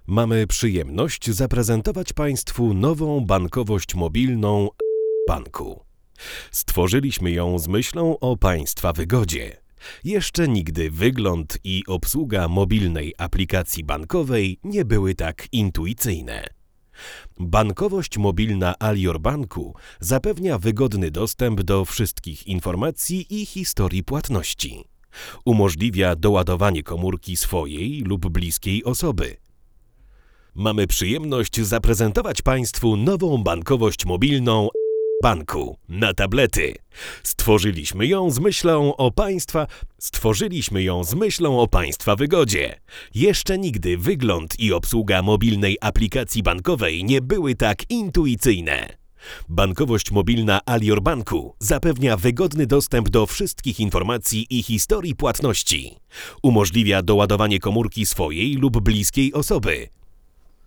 Zawsze nagrywamy lektorów w studiu Mikrofoniki, i zawsze stosujemy do porównań ten sam tor nagraniowy.
Co prawda automatycznie dostaliśmy do sygnału więcej niechcianego szumu, ale jest to szum równy, gładki, stabilny.
Bardzo przyjemne dla ucha, nasycone, zrównoważone, wydaje się naprawdę predysponowane do głosów lektorskich.